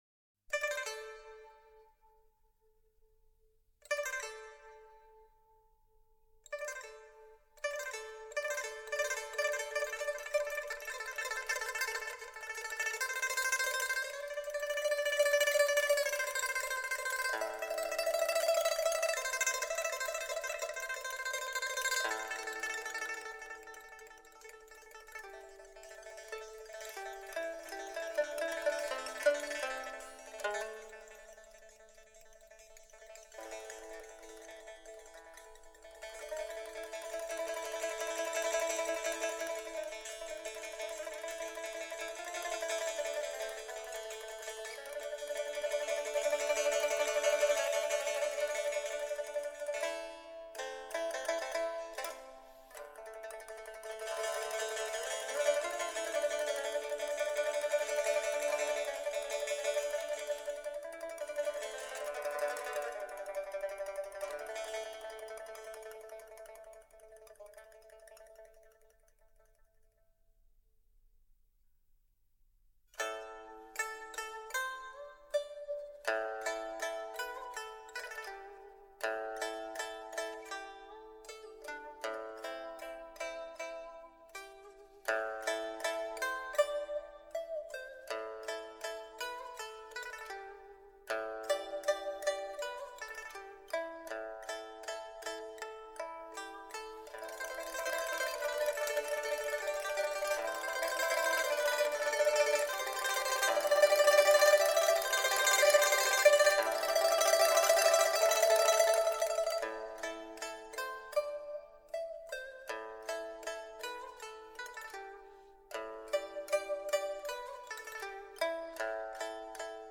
中国弹拨乐
琵琶